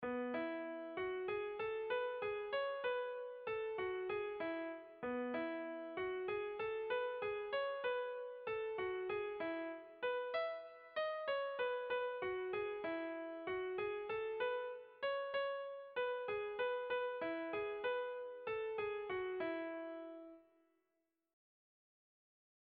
Irrizkoa
Zortziko txikia (hg) / Lau puntuko txikia (ip)
AABD